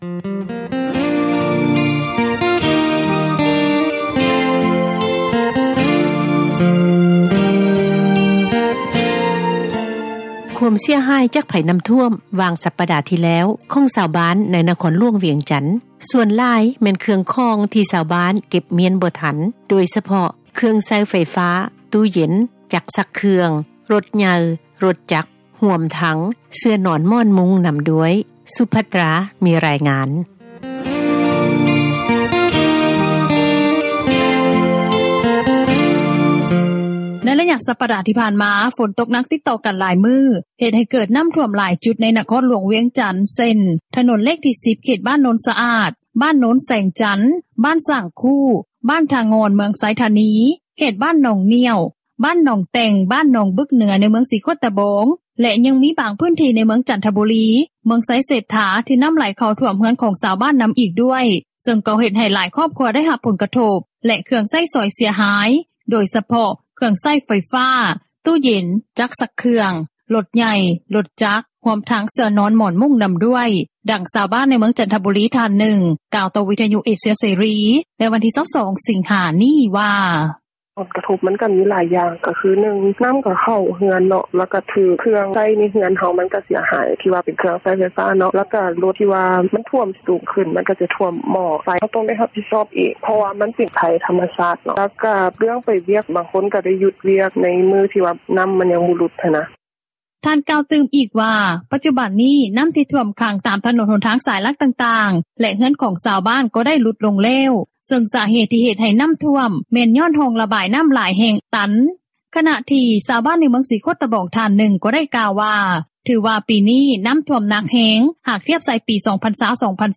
ດັ່ງຊາວບ້ານ ໃນເມືອງຈັນທະບູຣີ ທ່ານນຶ່ງ ກ່າວຕໍ່ວິທຍຸເອເຊັຽເສຣີ ໃນວັນທີ່ 22 ສິງຫານີ້ວ່າ: